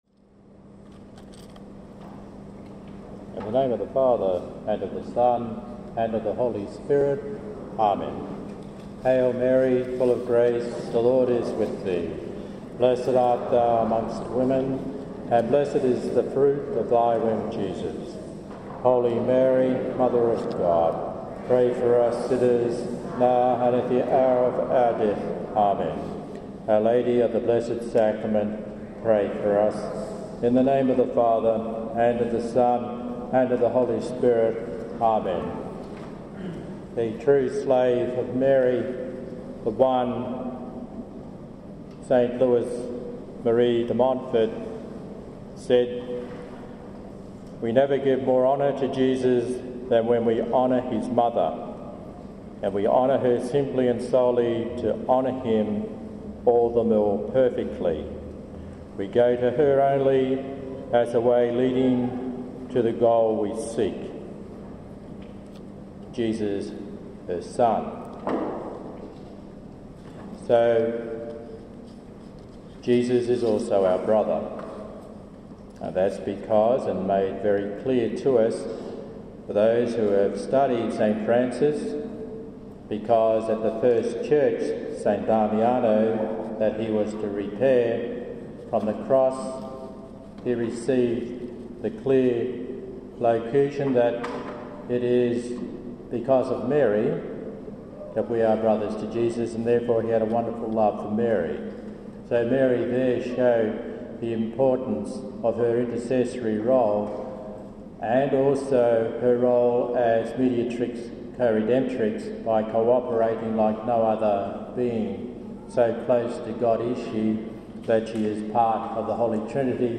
held at Our Lady Queen of Poland Church in Maylands, Western Australia on 7 January 2012.